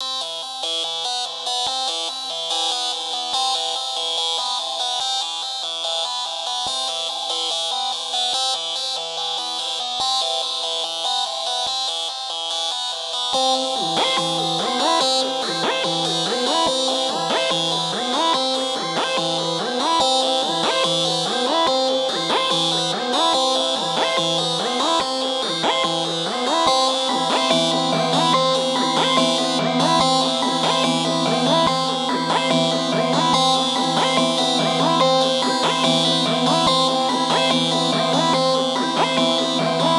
SOUTHSIDE_melody_loop_running_144_Em.wav